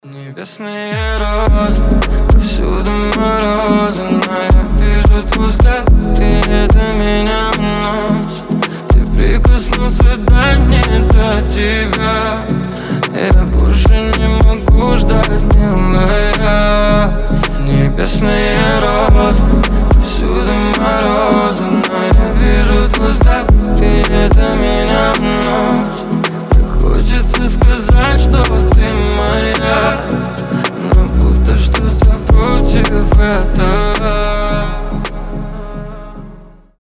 • Качество: 320, Stereo
лирика
красивый мужской голос